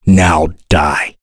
voices / heroes / en
Kain-Vox_Skill7-2_b.wav